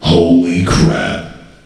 voc_holyshit.ogg